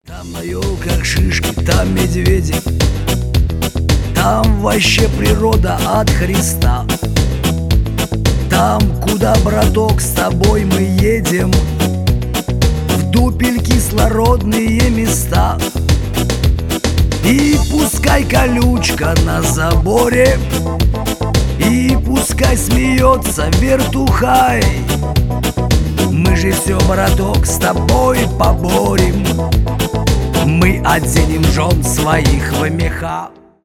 шансон
тюремная лирика